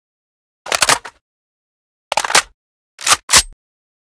57e0746fe6 Divergent / mods / Gewehr Reanimation / gamedata / sounds / weapons / librarian_g43 / reload_empty.ogg 14 KiB (Stored with Git LFS) Raw History
reload_empty.ogg